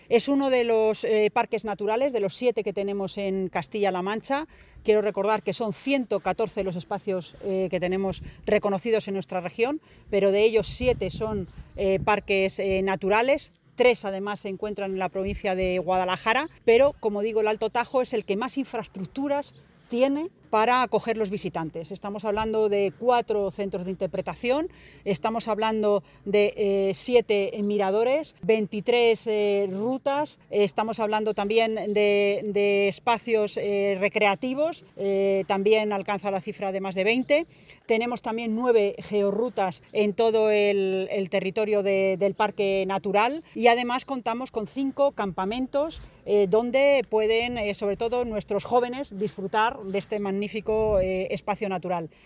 Consejería de Desarrollo Sostenible Lunes, 27 Octubre 2025 - 1:45pm La consejera de Desarrollo Sostenible, Mercedes Gómez, ha señalado hoy, durante el acto de celebración del 25 aniversario del Parque Natural del Alto Tajo, que es uno de los siete parques naturales que más infraestructuras tiene para acoger a visitantes, con cuatro centros de interpretación, siete miradores, 23 rutas, 20 espacios recreativos, nueve geo-rutas y cinco campamentos para que los jóvenes disfruten del parque natural. mercedes_gomez-parque_natural_con_mas_infraestructuras.mp3 Descargar: Descargar